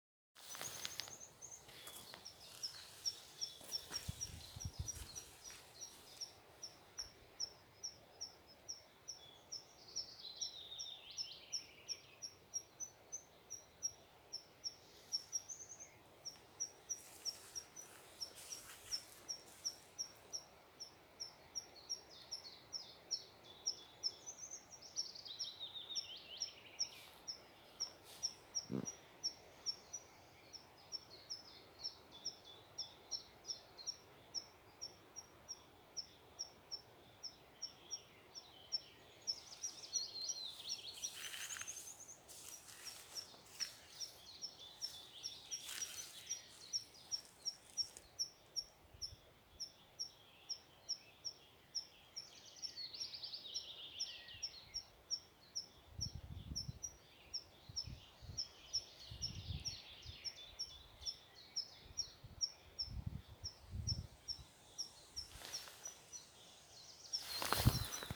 Птицы -> Коньки ->
лесной конек, Anthus trivialis
СтатусВзволнованное поведение или крики